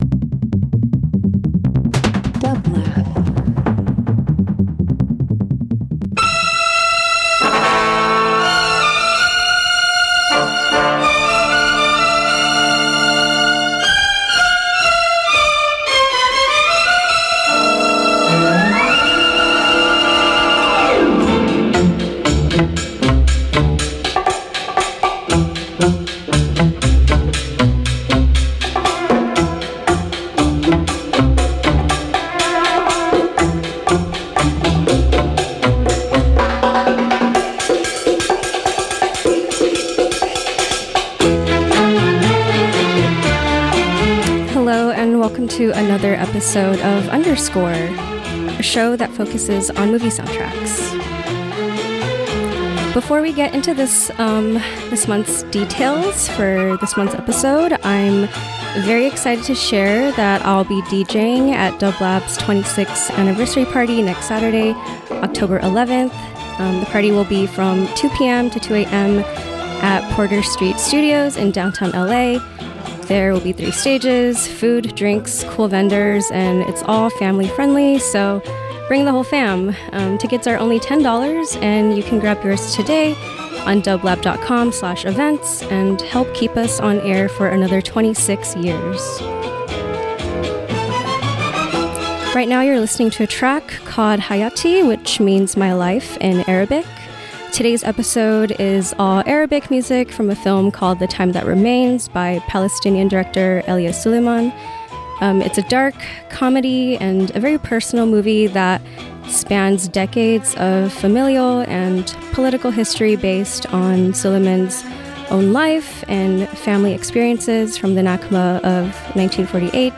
Soul Soundtracks Traditional